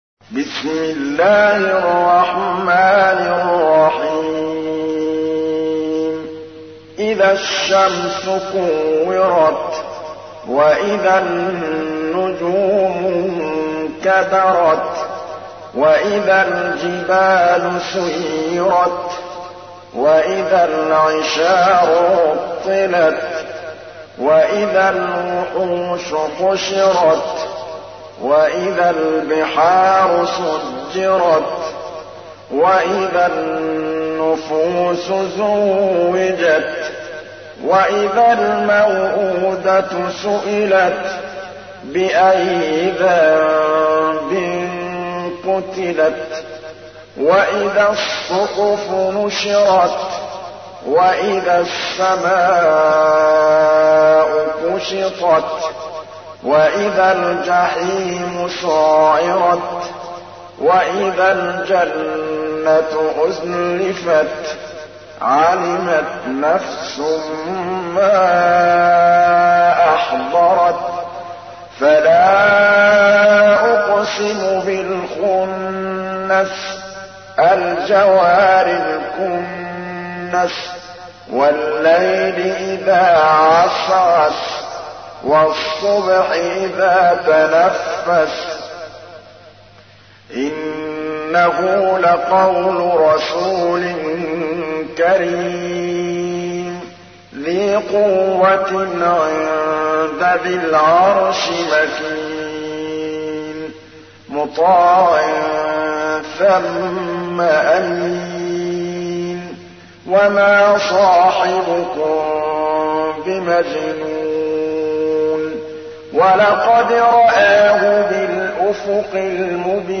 تحميل : 81. سورة التكوير / القارئ محمود الطبلاوي / القرآن الكريم / موقع يا حسين